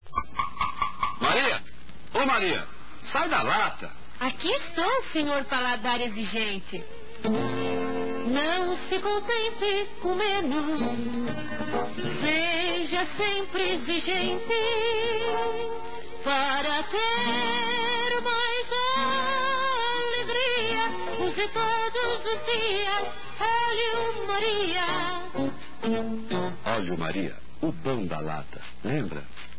Anos 1950, Brasil, Rádio